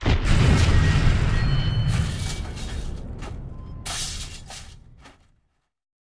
Single explosion with light debris.